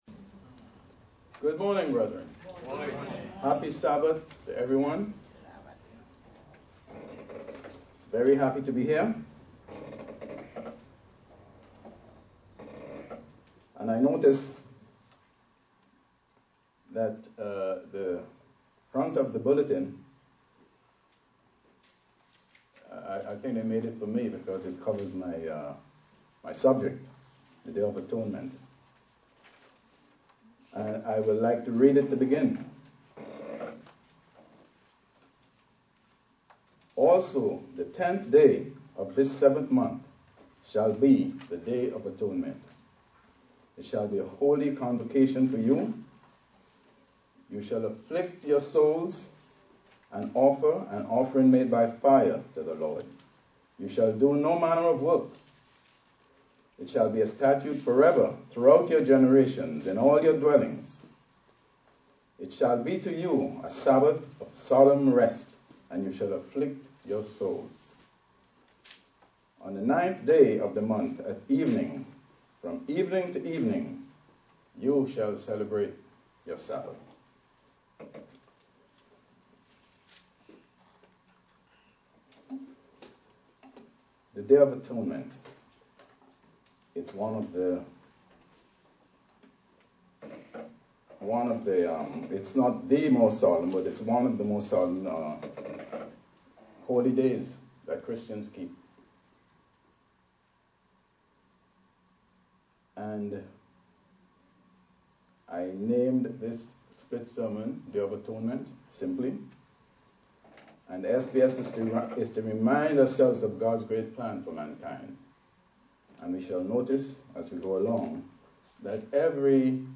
UCG Sermon Studying the bible?
Given in New York City, NY